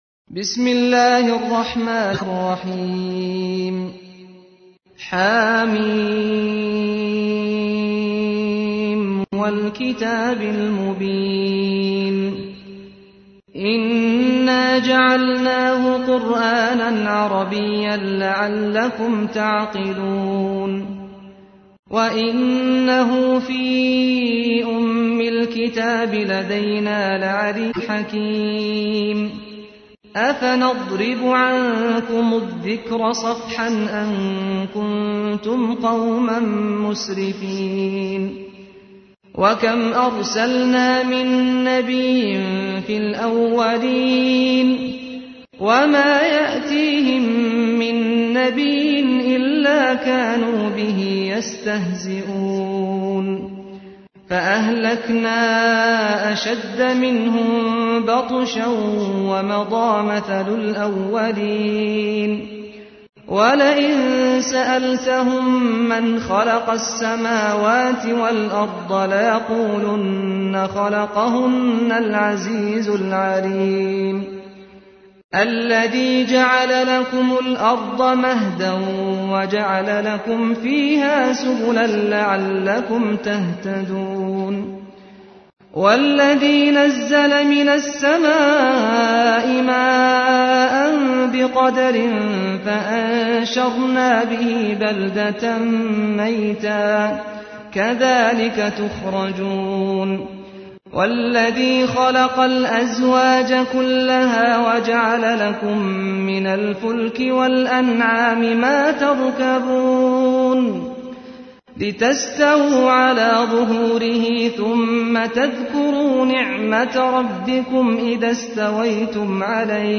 تحميل : 43. سورة الزخرف / القارئ سعد الغامدي / القرآن الكريم / موقع يا حسين